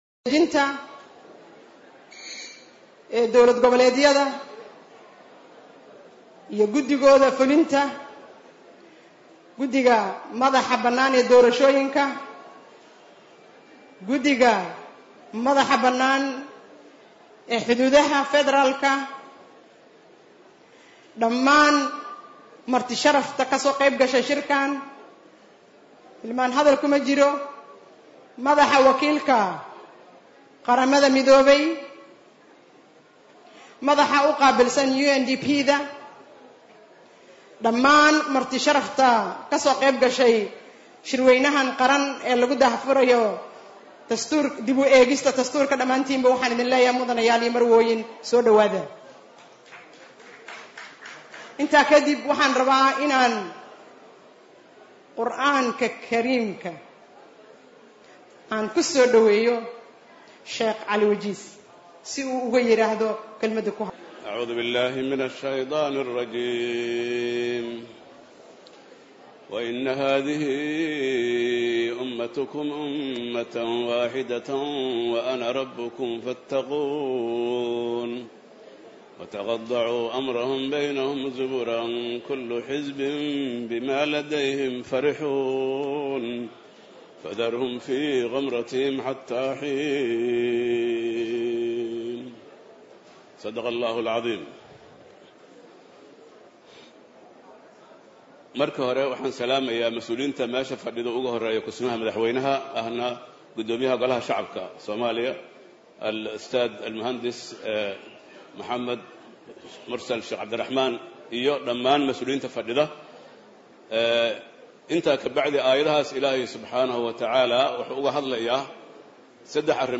{WARSANRADIO} Waxaa maanta la soo gabagabeeyay shirweynaha Qaran ee daah furka dib u eegista Dastuurka, kaasoo ay ka qeyb galeen 300 oo ruux oo ka kala socday qeybaha kala duwan ee bulshada, xubno ka socday Baarlamaanka Federaalka, Xukuumadda, Aqalka sare, dowlad goboleedyada.
Kusimaha Madaxweynaha ahna Guddoomiyaja Golaha Shacabka Maxamed Mursal ayaa shirka soo xiray, waxaana uu ku dheeraaday ahmiyada uu lahaa shirkan.
Wasiirka Arrimaha Dastuurka C/raxmaan Xoosh Jibriil ayaa ka sheegay marxaladihii uu soo maray shirka iyo howlihii soo socday, waxaa uu ka dhawaajiyay in sababaha shirka ay uga maqan tahay Jubbaland aysan aheyn Dastuur naceyb, balse arrimo kale ay jiraan.